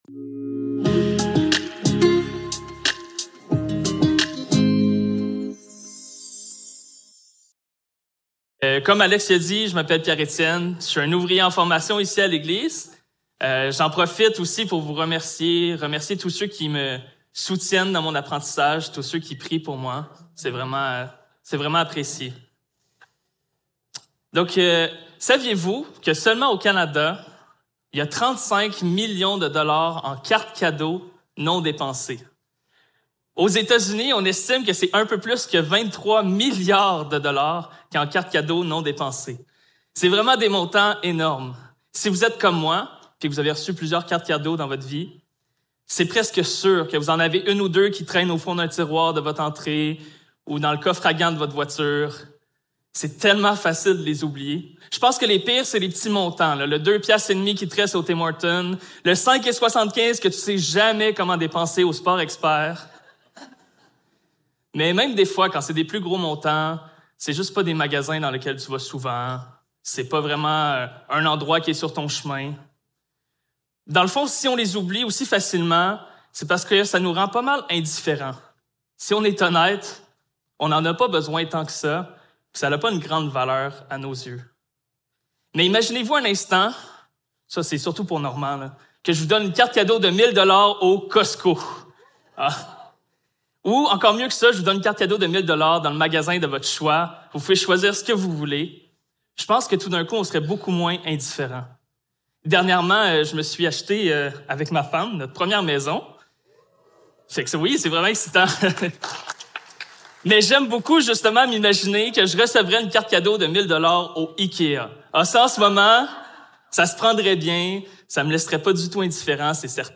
Luc 7.36-50 Service Type: Célébration dimanche matin Description